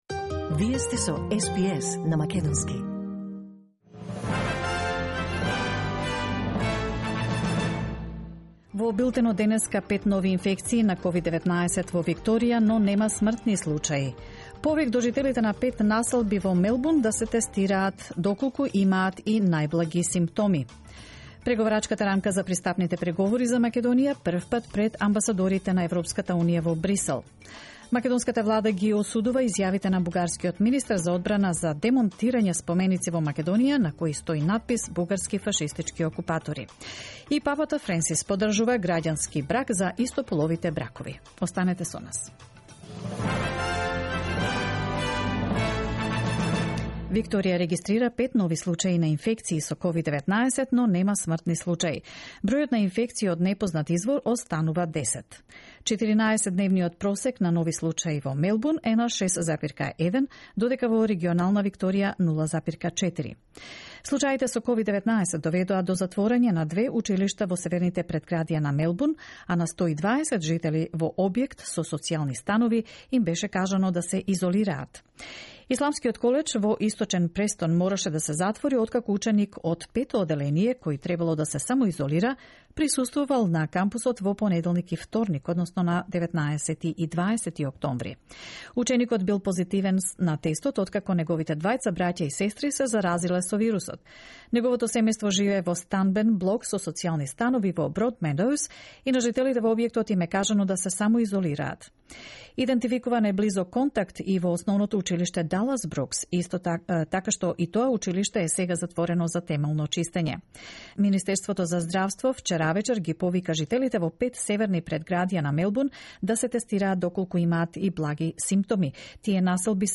SBS News in Macedonian, 22 October 2020